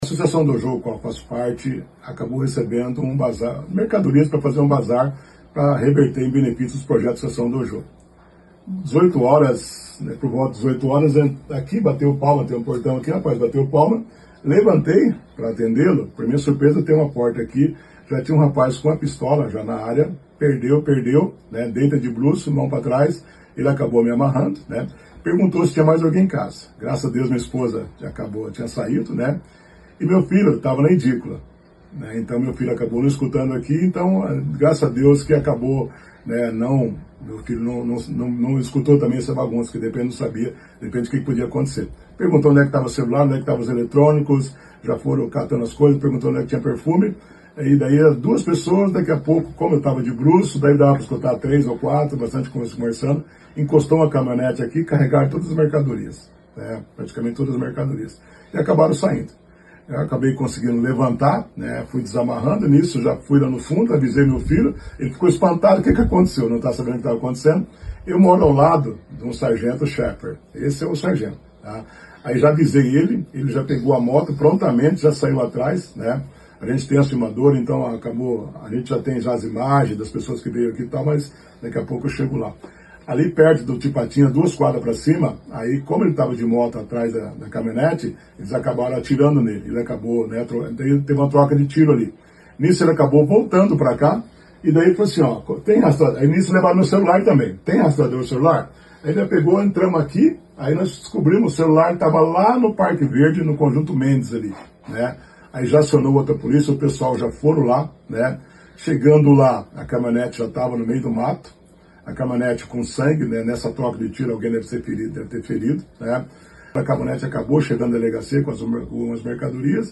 Ouça o que diz o ex-vereador: